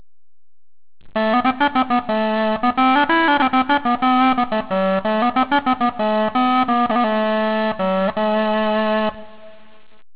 Early Music - Kortholt, a double reeded windcap instrument producing a characteristic buzzing sound
The kortholt (pronounced "kort-holt") is part of the wider family of renaissance "windcap" instruments, using a double reed inside a windcap to produce the characteristic windcap buzzing sound.
Kortholt Sound Clips